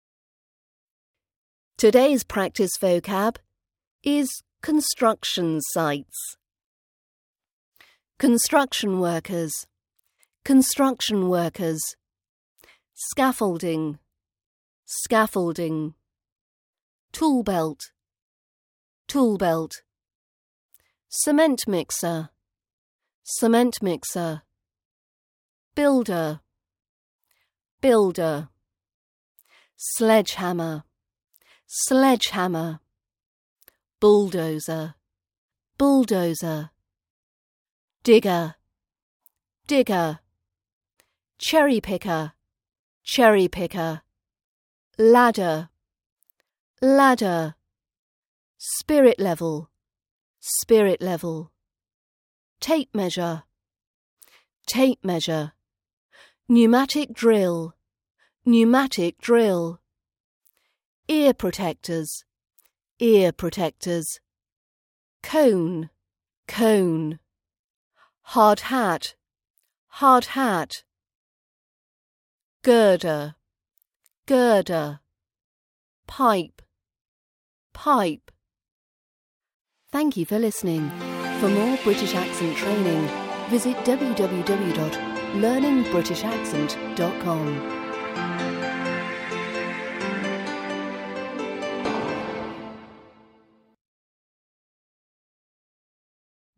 Would you like to teach yourself to speak with a RP British accent?